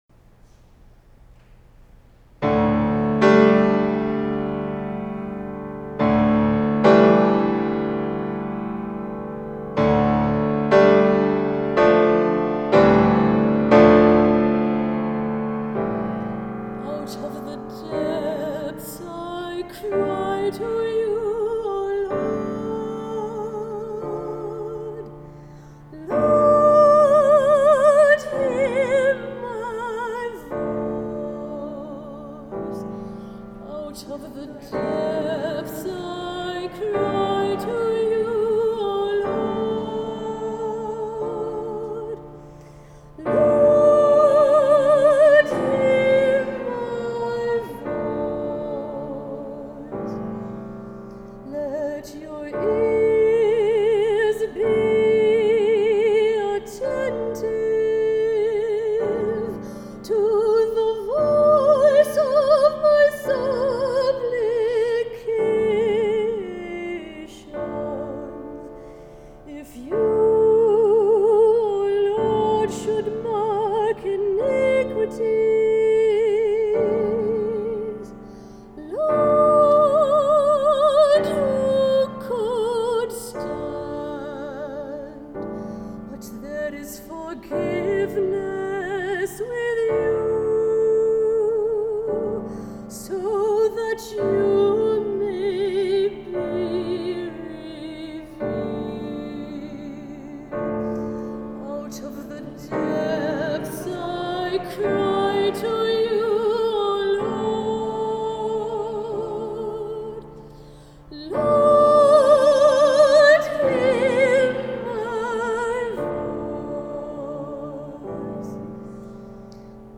A Musical Setting of Psalm 130
Many years ago, I set Psalm 130 to music for congregational singing.